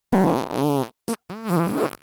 FART SOUND 55